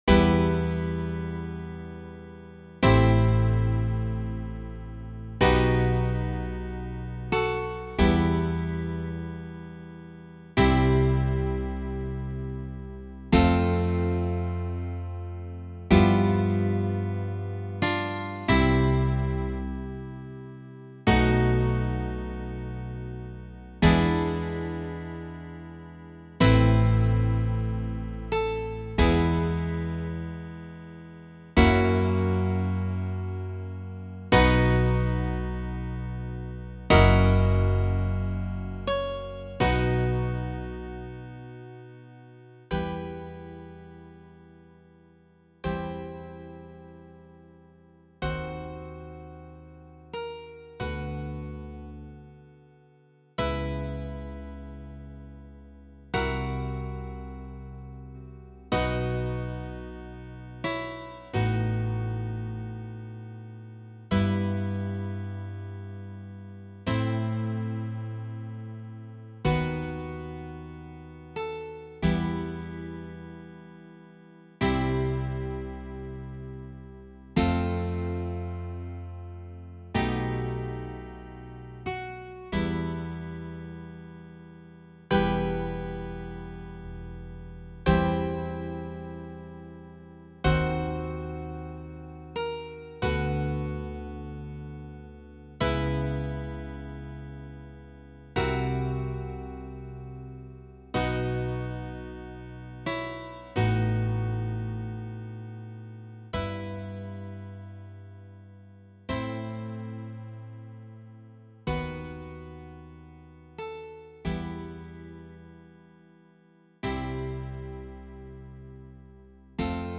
Zupforchester, ,